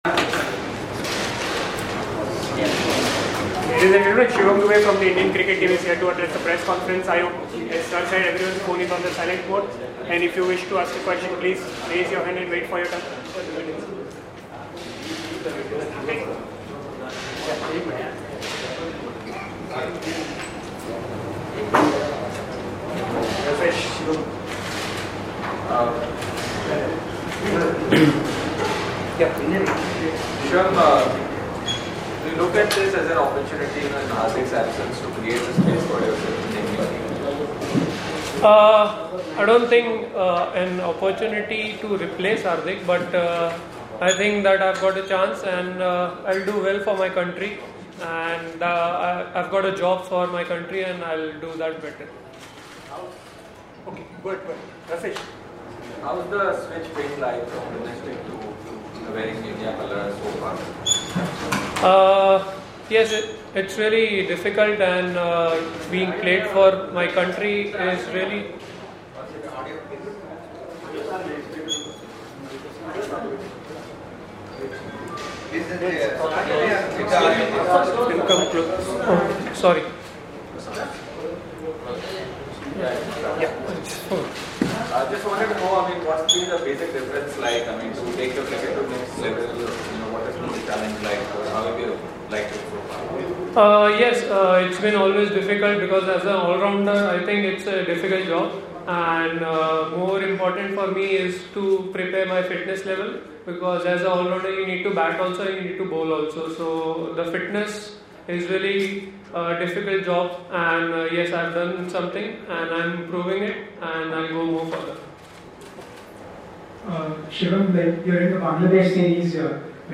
Shivam Dube Speaks After India Training Session